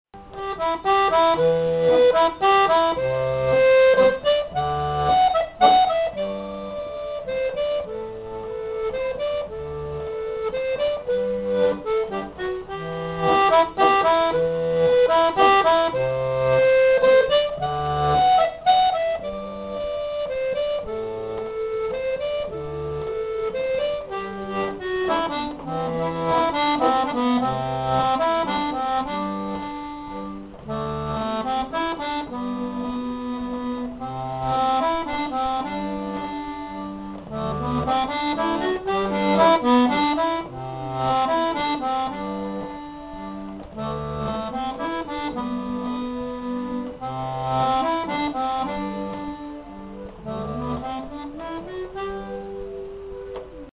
l'atelier d'accordéon diatonique
le contrechant